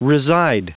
Prononciation du mot reside en anglais (fichier audio)
Prononciation du mot : reside